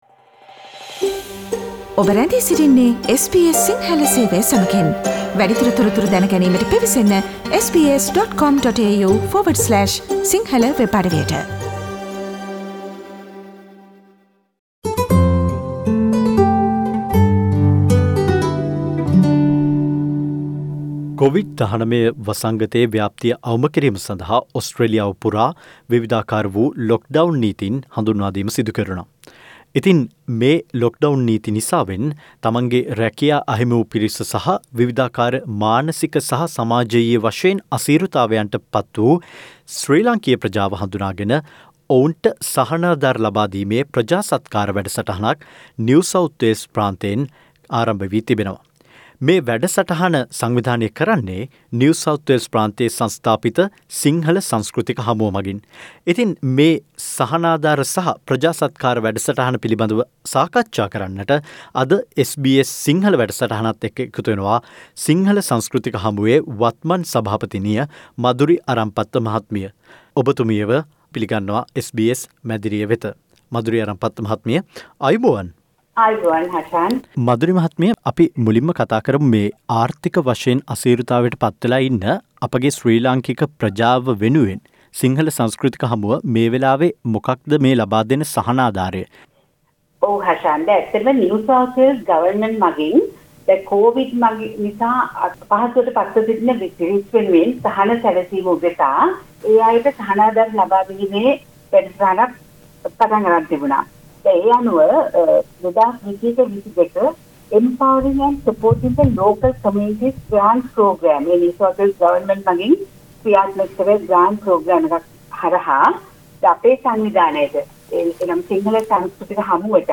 SBS සිංහල සිදුකල සාකච්ඡාවට සවන්දෙන්න.